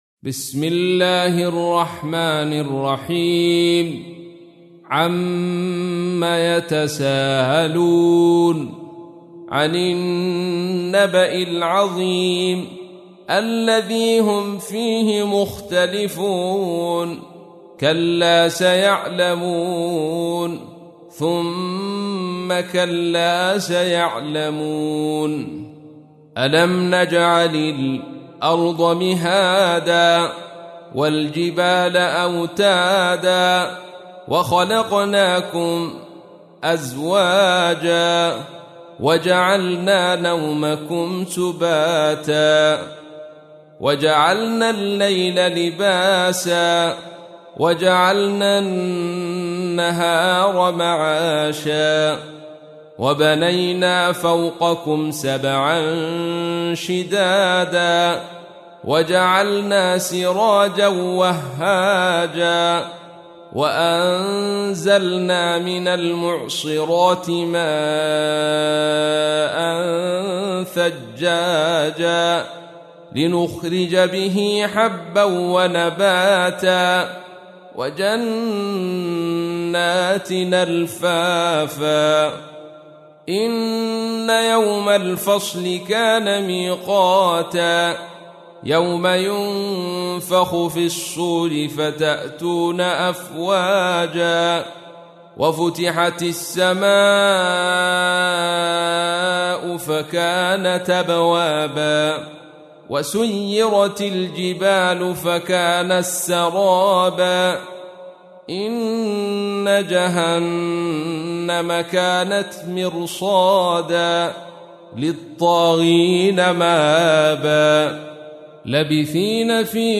تحميل : 78. سورة النبأ / القارئ عبد الرشيد صوفي / القرآن الكريم / موقع يا حسين